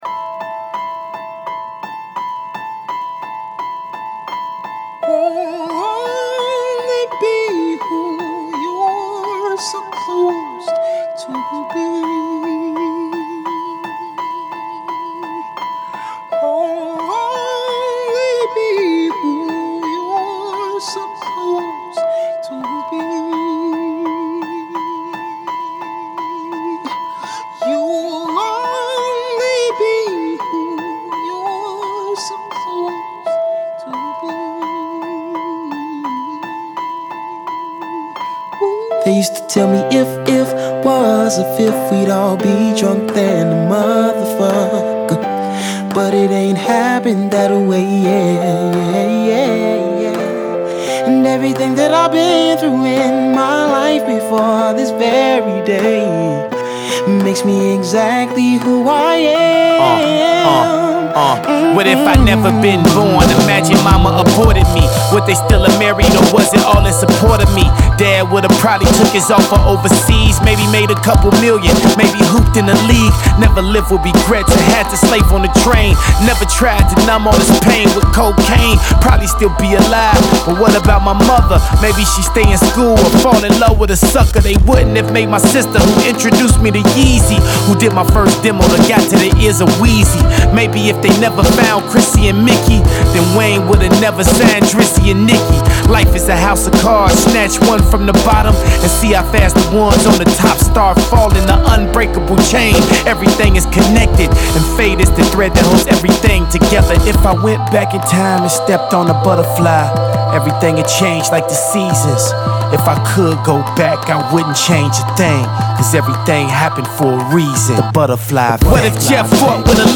His stuff isn’t at all considered fast food hip-hop.